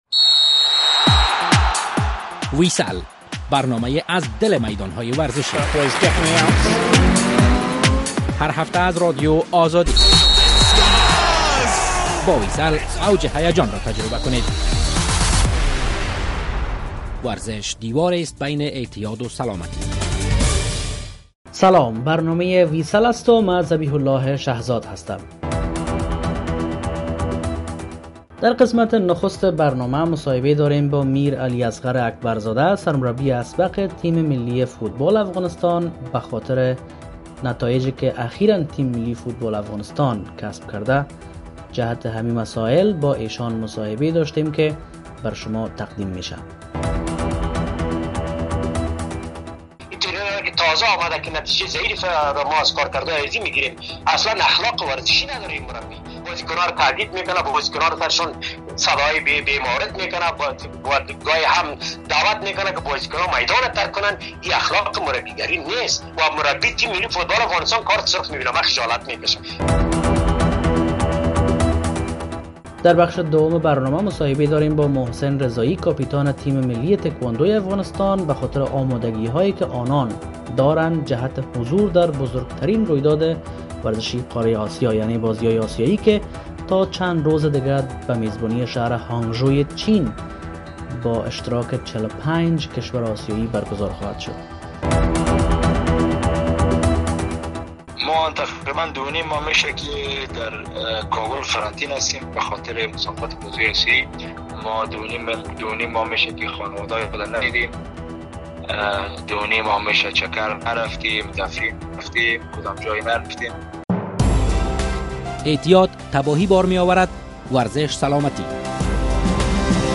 مصاحبه شده است